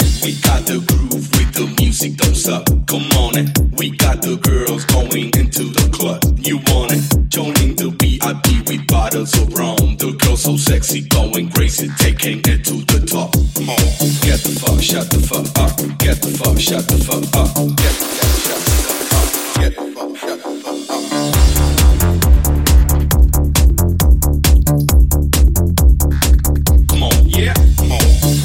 tech house hits
Genere: house,tec house,tecno,remix,hit